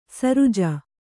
♪ saruja